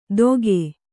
♪ doge